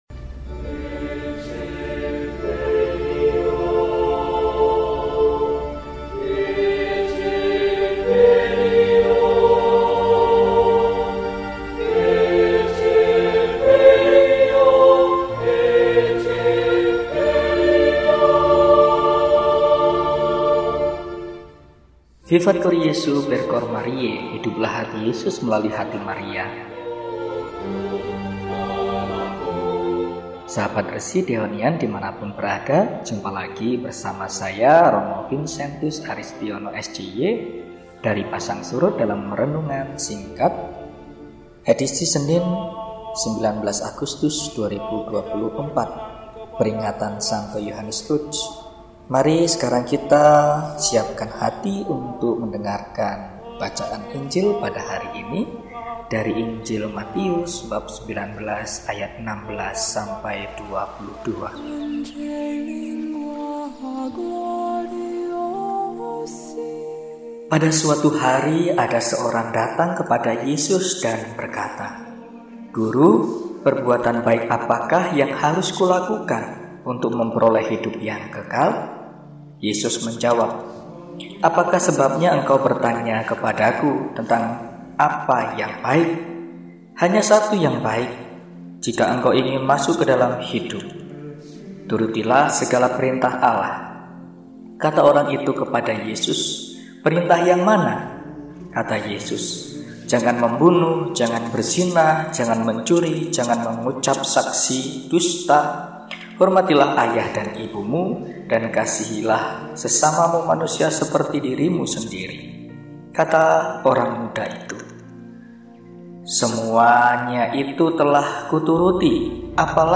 RESI (Renungan Singkat) DEHONIAN